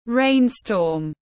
Rain storm /ˈreɪn.stɔːm/